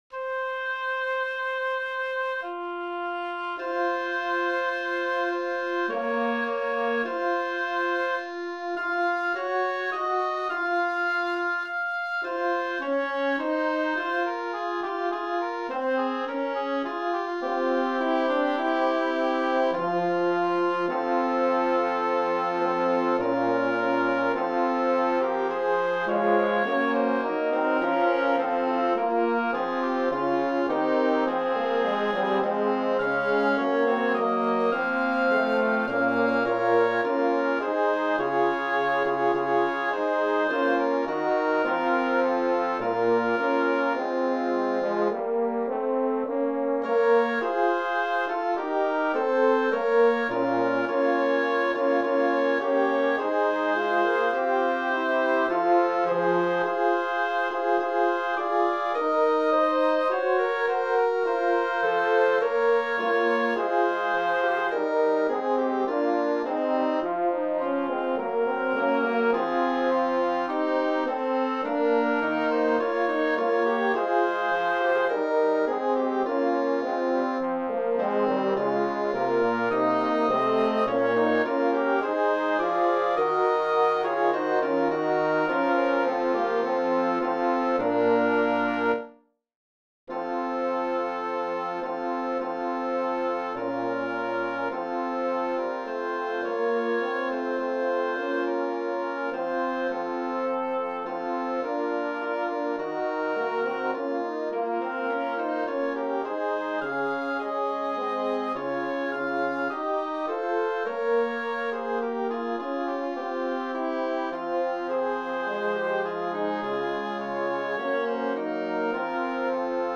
Ensemble: Band/Wind Ensemble